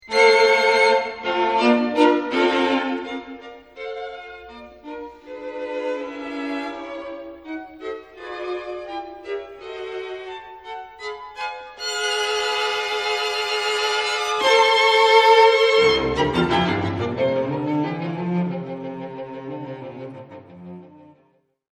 muziektheorieanalyse klassieke stukken  > Beethoven: strijkkwartet in F gr.t.  op. 59 nr.1